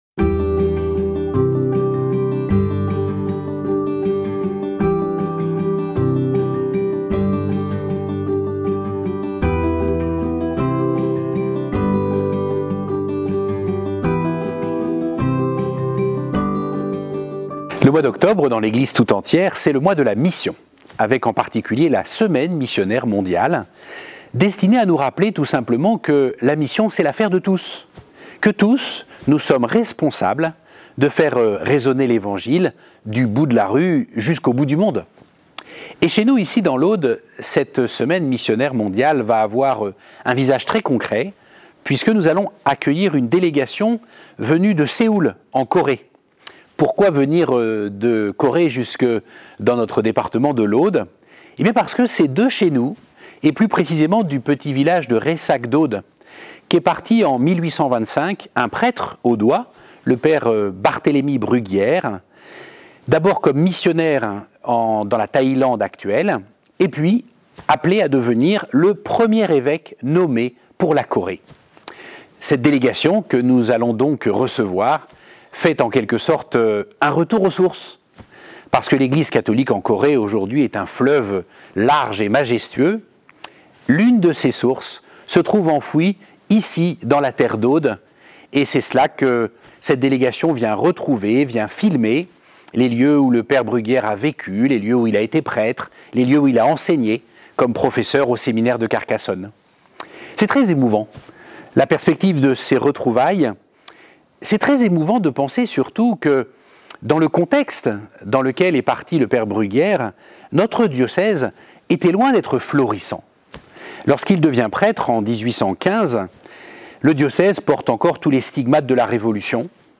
Soyons donc audacieux en suivant l'exemple de Mgr Barthélemy Bruguière, prêtre audois et premier évêque de Corée, actuellement en voie de béatification. Tel est l'appel de notre évêque en ce début de mois